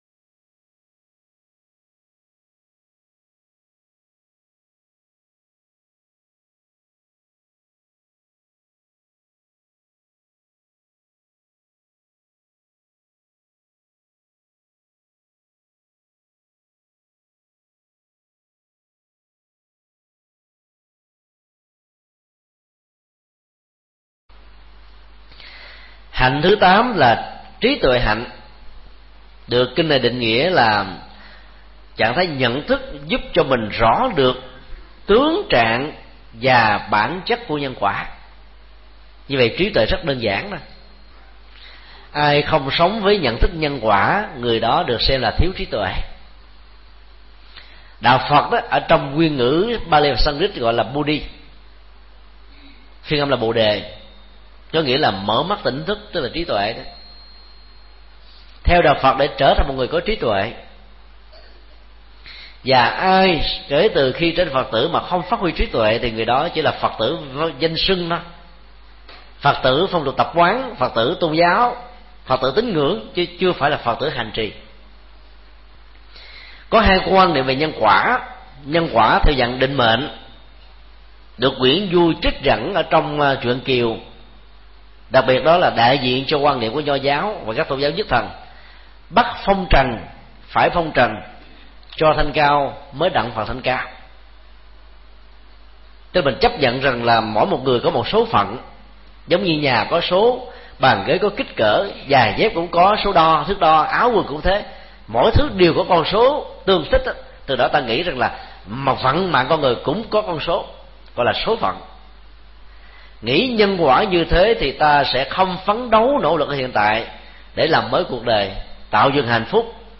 Pháp thoại
Phần 2/2 tại Chùa Long Khánh – Tỉnh hội Phật Giáo Bình Định